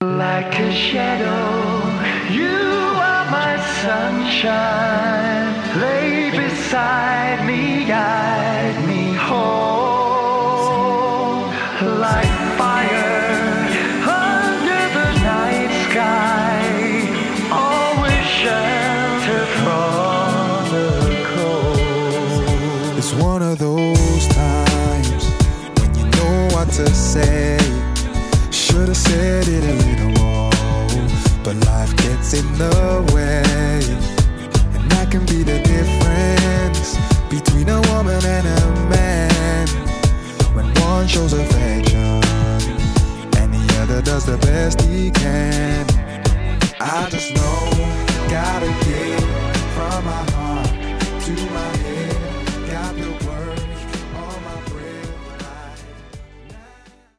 featured vocal